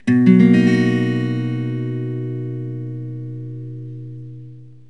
Bmaj7.mp3